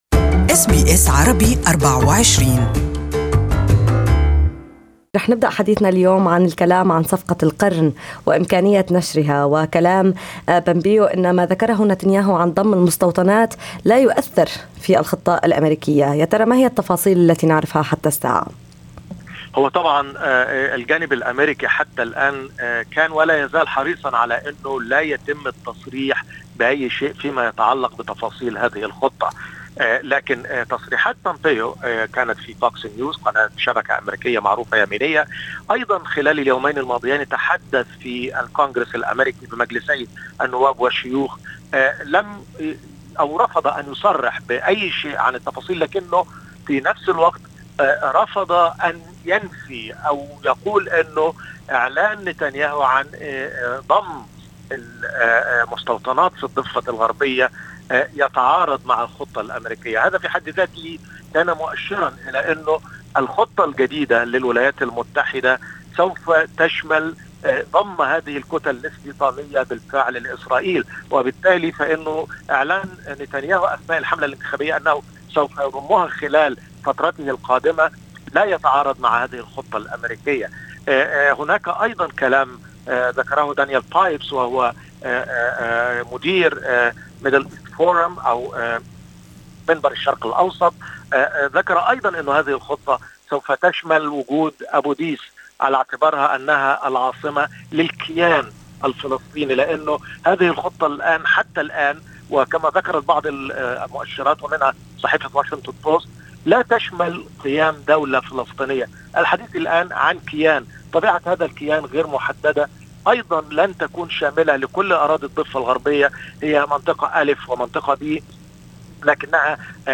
SBS Arabic